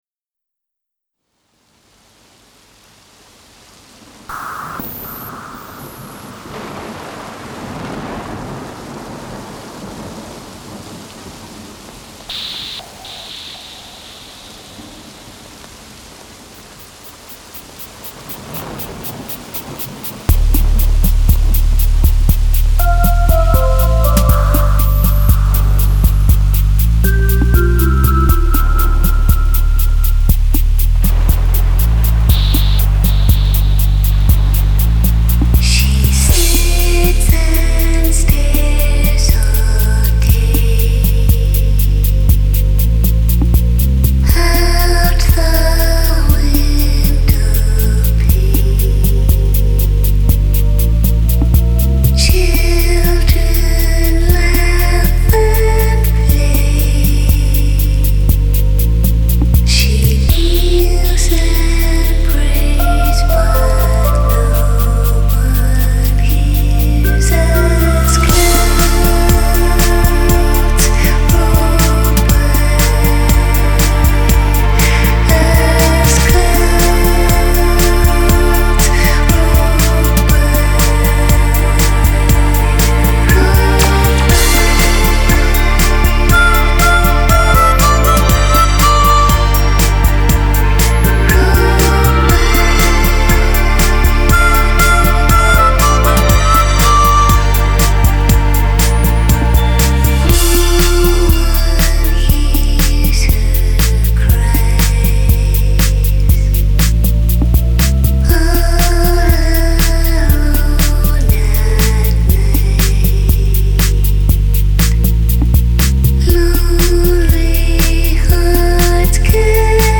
Genre: Deep House, Downtempo.